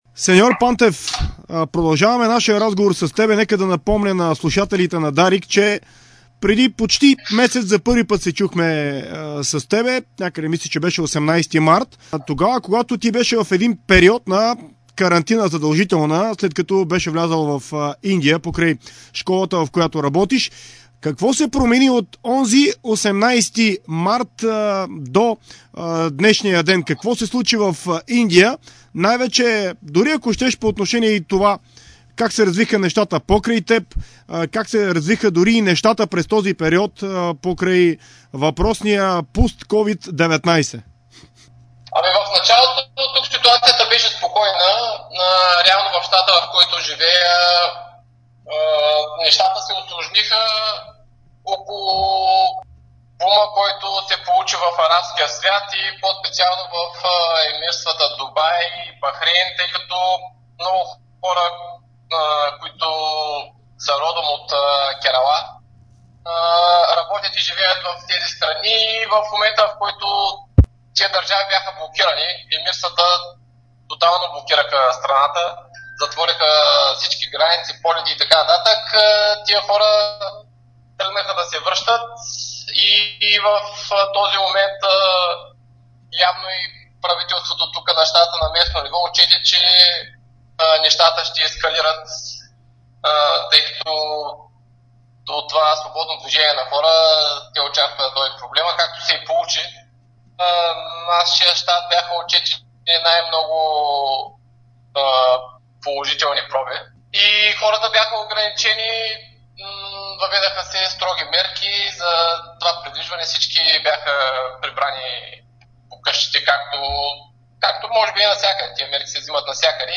Той говори и за това, кога се очаква в Индия да се възобнови спортният живот. Чуйте цялото интервю с него в приложения звуков файл.